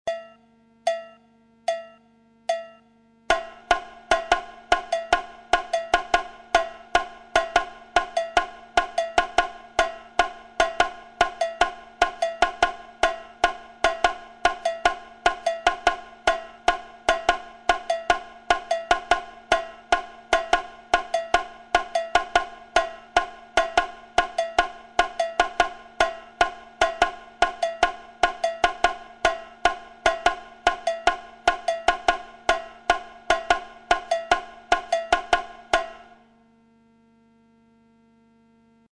Le tambourim figure 2
Une autre figure courante de tambourim dans la bossa.